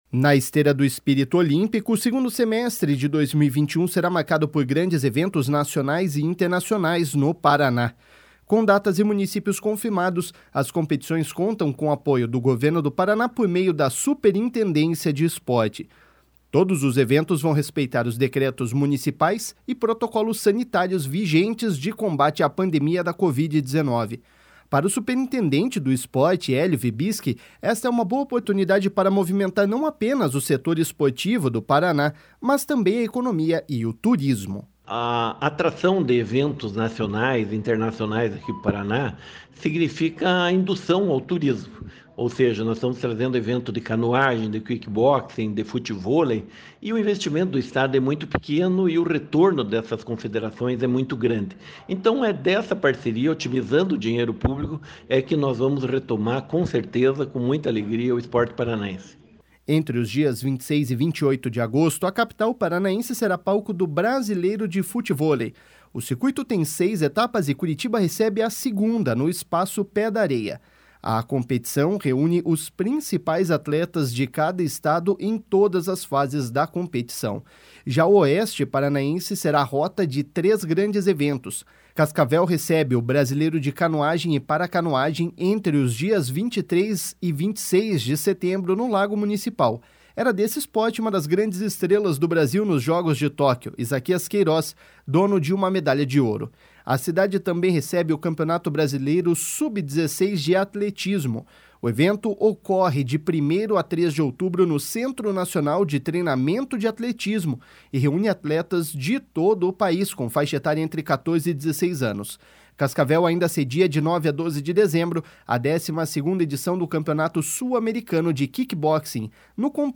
Para o superintendente do Esporte, Helio Wirbiski, esta é uma boa oportunidade para movimentar não apenas o setor esportivo no Paraná, mas também a economia e o turismo.// SONORA HELIO WIRBISKI.//